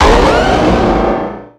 Cri de Méga-Carchacrok dans Pokémon X et Y.
Cri_0445_Méga_XY.ogg